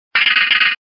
Archivo:Grito de Banette.ogg